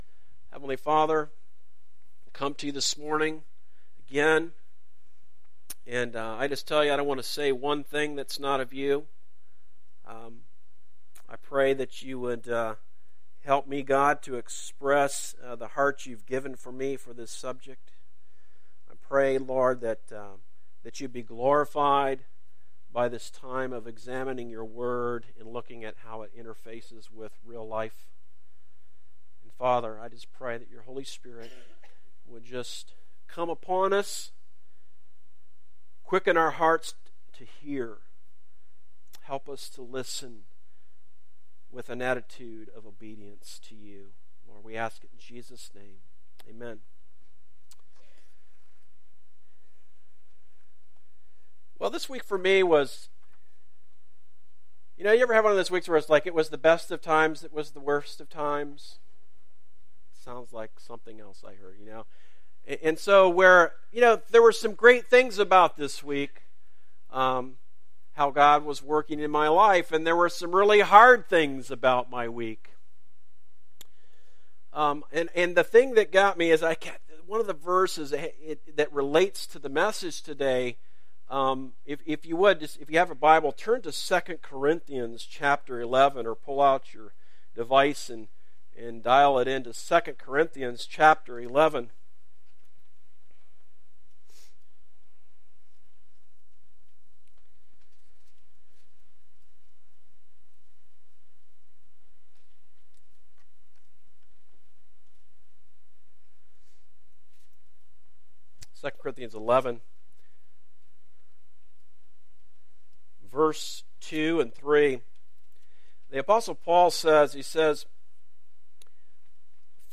A message from the series "1 Samuel."